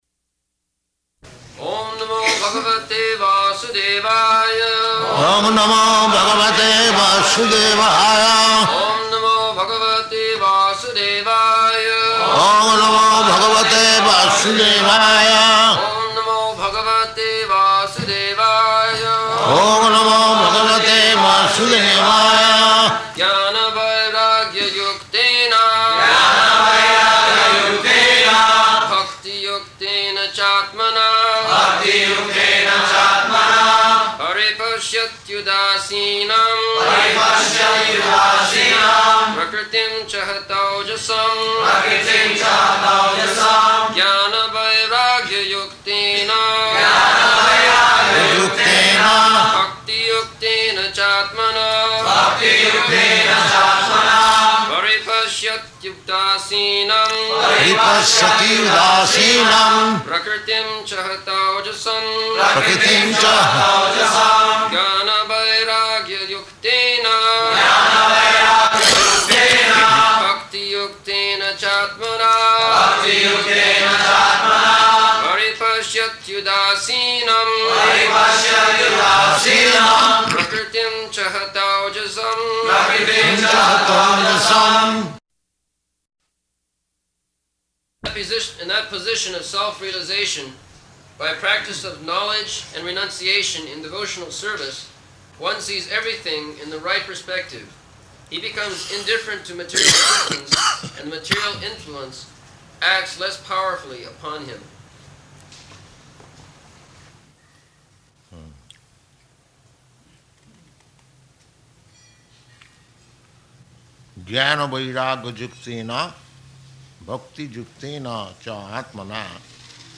November 18th 1974 Location: Bombay Audio file
[Prabhupāda and devotees repeat] [leads chanting of verse, etc.]